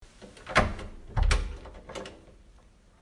门 " 门
描述：前门的声音和门锁中钥匙的旋转
Tag: 打开 关闭 关闭 门大满贯 走廊 大满贯 开锁 钥匙 打开 钥匙